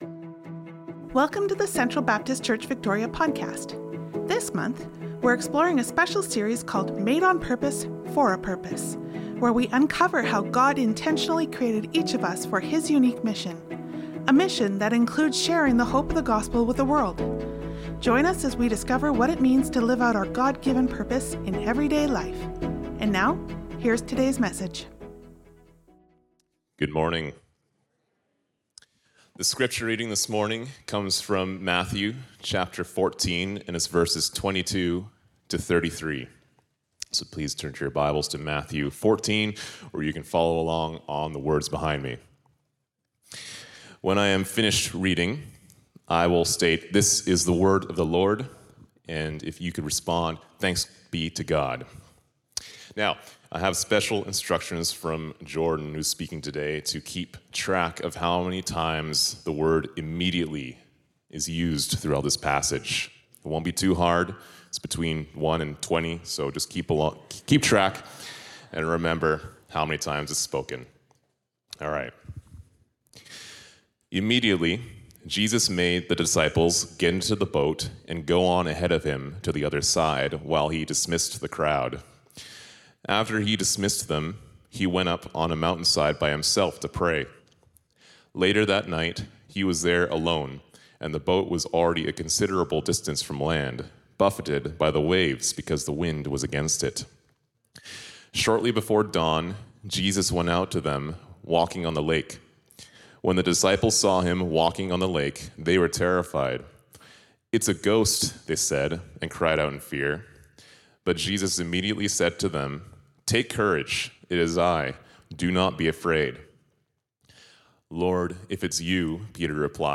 Sermons | Central Baptist Church
November 2, 2025 Guest Speaker Download Download Reference Matthew 14:22-33 Sermon Notes Nov 2'25.Worship Folder.pdf Nov 2'25.Sermon Notes.